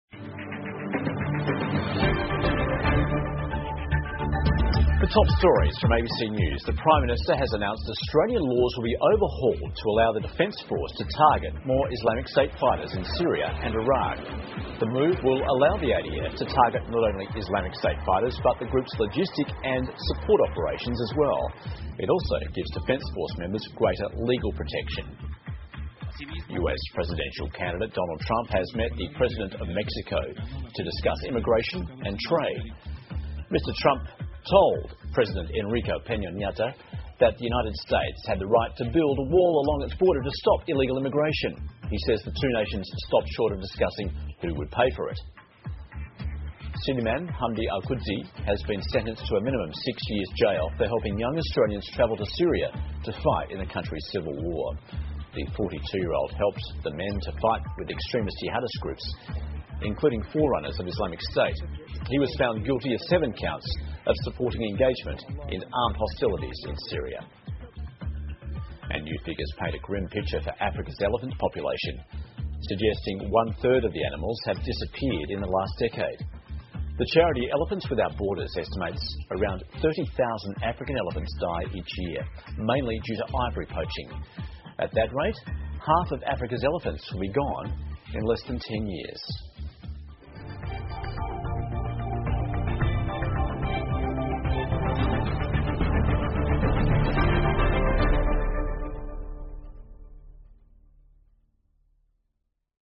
澳洲新闻 (ABC新闻快递) 澳大利亚改革法律允许打击ISIS 特朗普同墨西哥总统会晤 听力文件下载—在线英语听力室